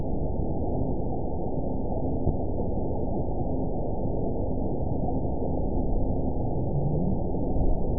event 920584 date 03/31/24 time 06:10:45 GMT (1 year, 1 month ago) score 9.19 location TSS-AB01 detected by nrw target species NRW annotations +NRW Spectrogram: Frequency (kHz) vs. Time (s) audio not available .wav